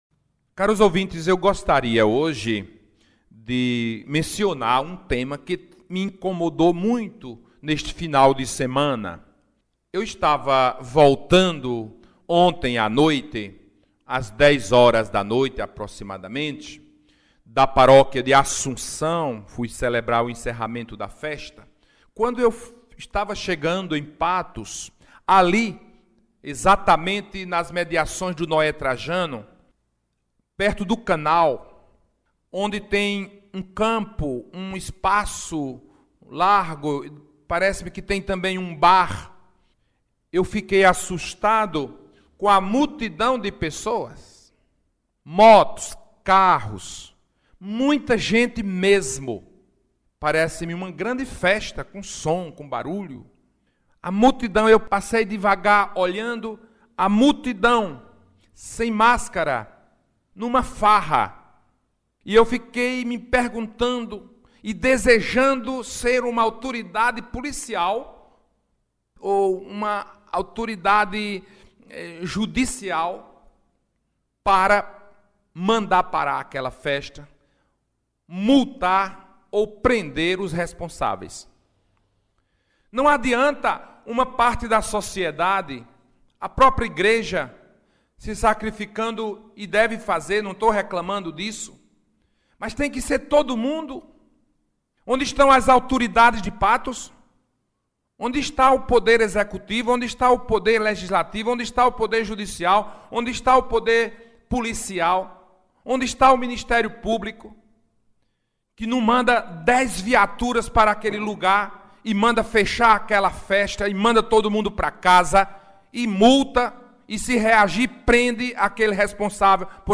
O bispo diocesano de Patos, Dom Eraldo Bispo da Silva, mostrou sua indignação em seu programa Palavra de Fé, quanto ao relaxamento social da população de Patos em relação aos cuidados com a covid-19, na noite deste domingo em uma área próxima ao conjunto Noé Trajano em Patos.
Áudio – Rádio Espinharas